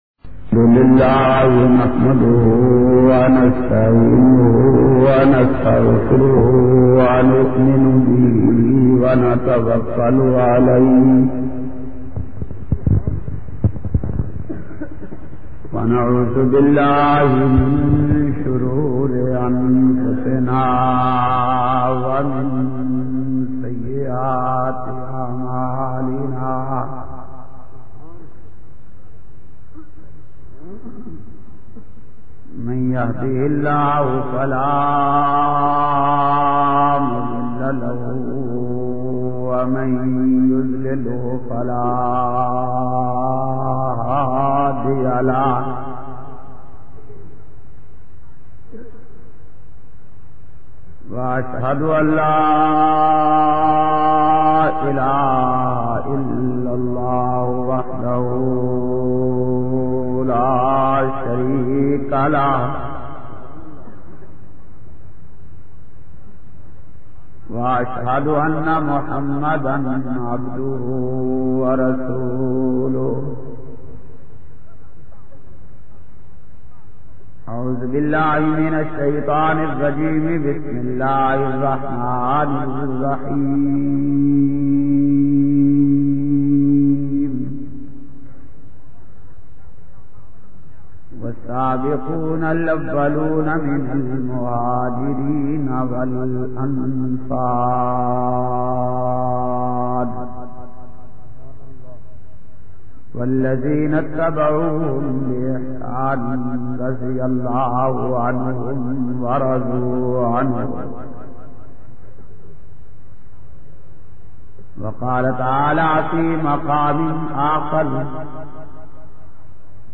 121- Shan e Hazrat Ali r.a bayan-1.mp3